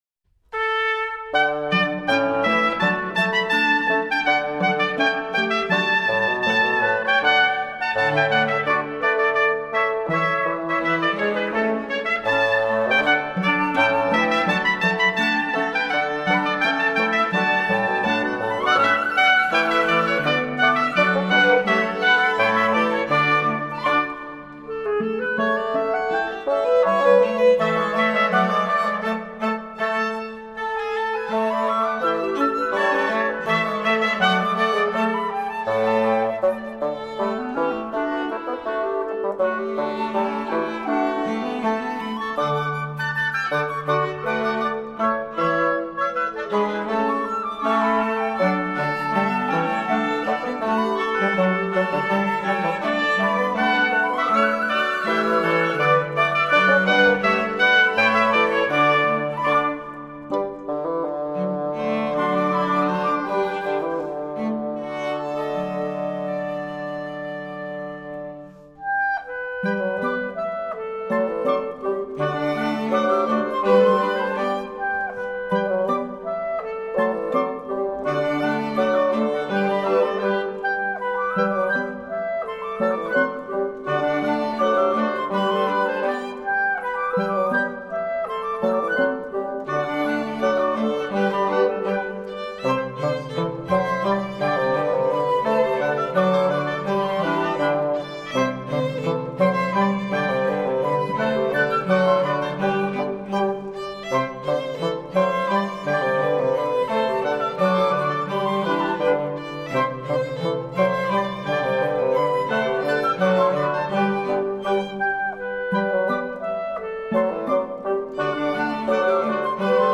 Zurich Boys’ Choir – The most beautiful Swiss folk songs and tunes (Vol. 2)
Burechätzeli-Tanz (Polka)